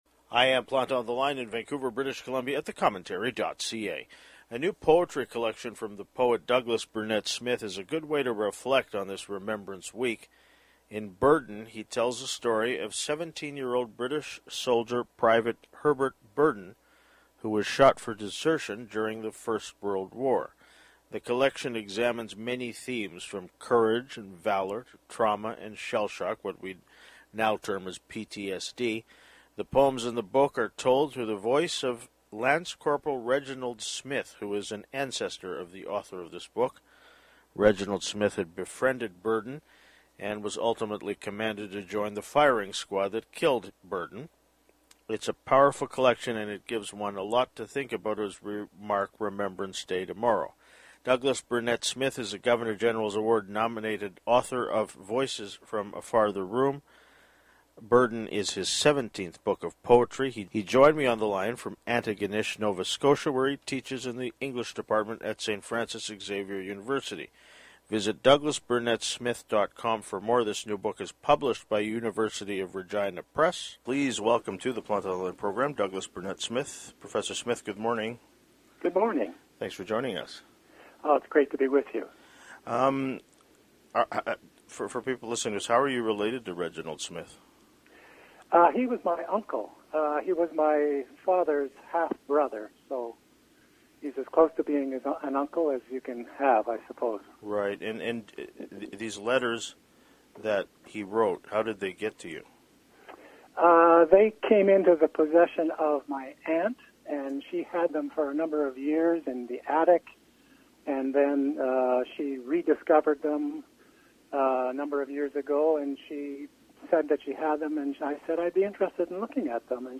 He joined me on the line from Antigonish, Nova Scotia, where he teaches in the English Department at St. Frances Xavier University.